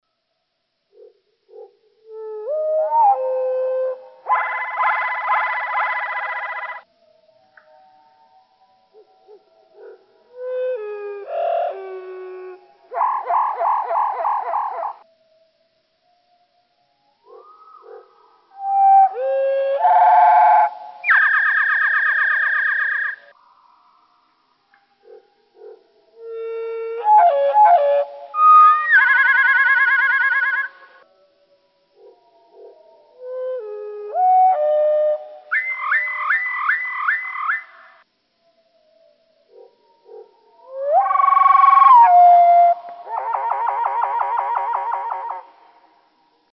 ♫117. The first six wood thrush songs in ♫116 slowed down to quarter speed. (0:46)
117_Wood_Thrush.mp3